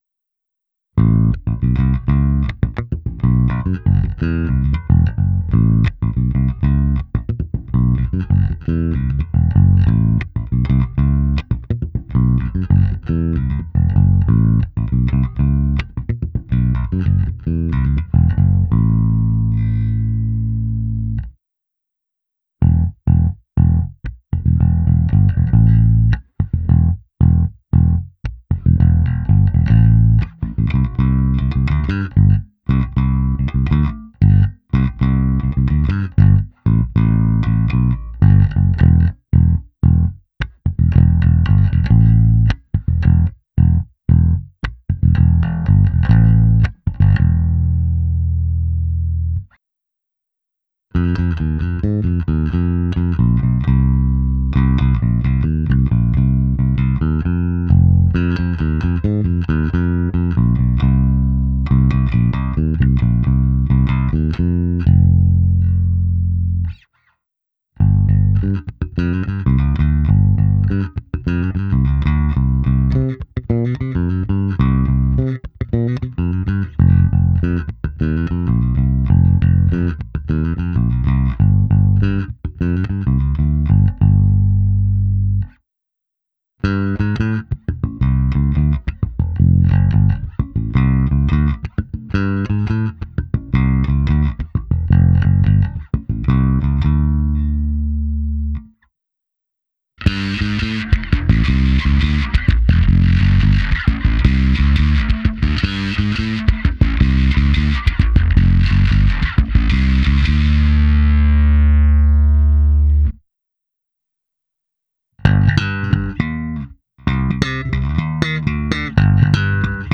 Zvuk je pevný, průrazný, tlačí.
Nahrávka se simulací aparátu, kde bylo použito i zkreslení a hra slapem. Hráno na oba snímače.